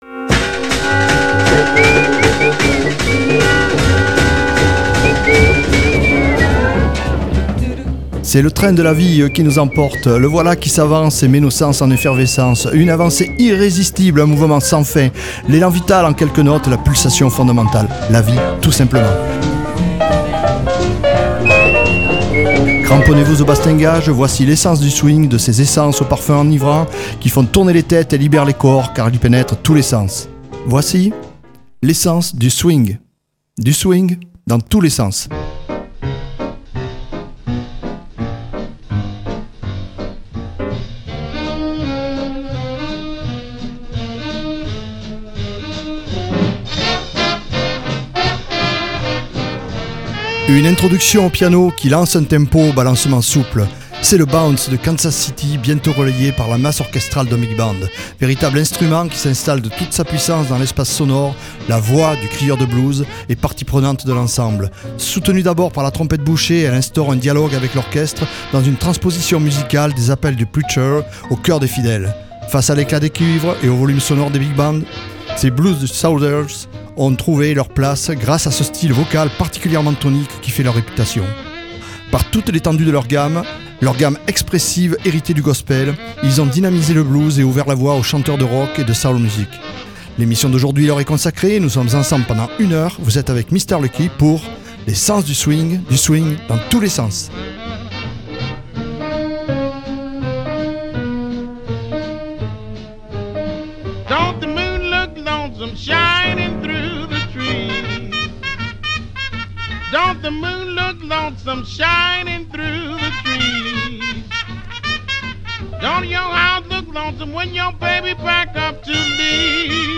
Pour rivaliser avec la puissance sonore des sax et des cuivres, les chanteurs de blues ont fait appel à leur patrimoine musical en mettant à contribution leur paire de poumons. La nécessite de percer la masse orchestrale des Big Bands les a transformés en crieurs de blues, ces fameux blues shouters qui ont croisé le cri des travailleurs dans les champs de coton avec la véhémence des invectives du preacher lors des offices religieux.